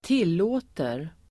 Uttal: [²t'il:å:ter]